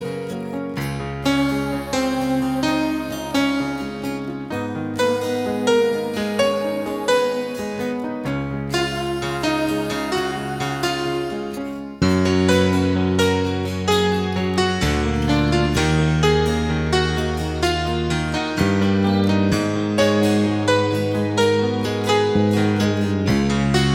One Semitone Down Pop (1970s) 4:57 Buy £1.50